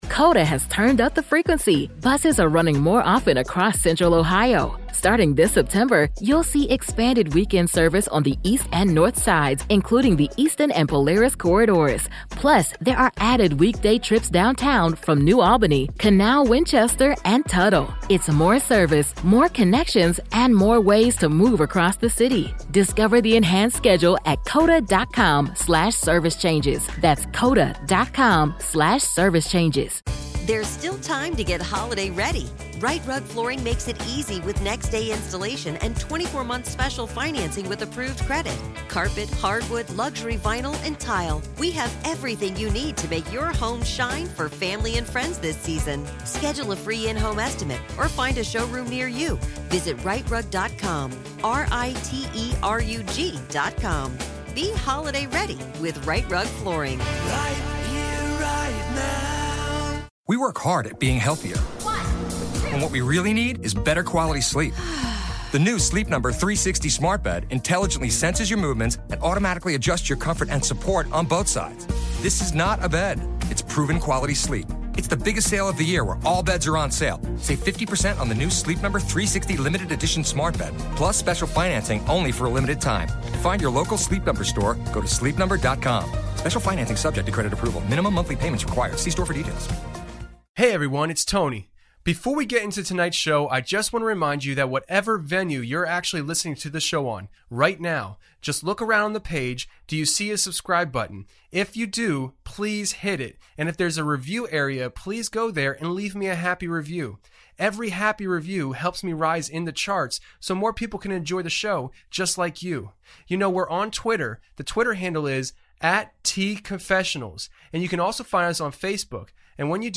Interview Audio Clips
Outro Instrumental